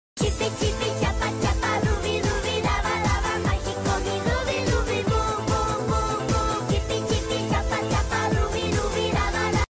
Cute bunny singing . sound effects free download